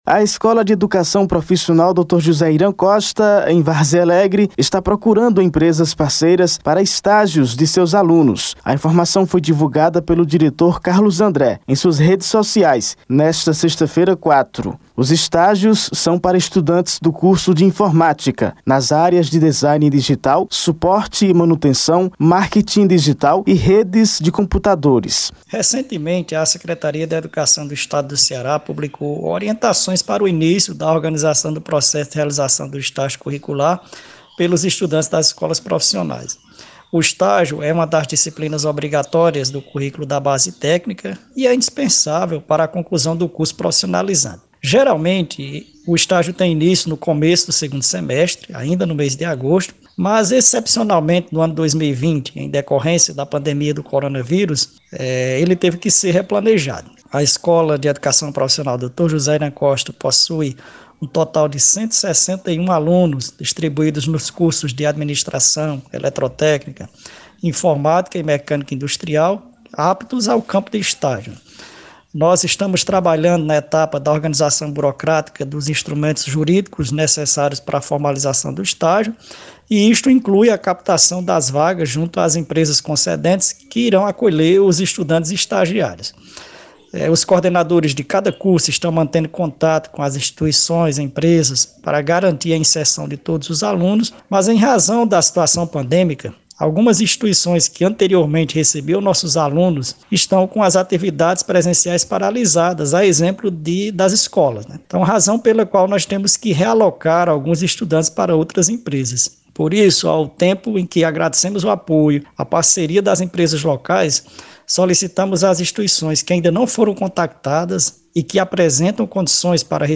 Confira o áudio da reportagem: Foto de alunos na escola quando havia aulas presenciais | Redes sociais